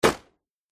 • 声道 立體聲 (2ch)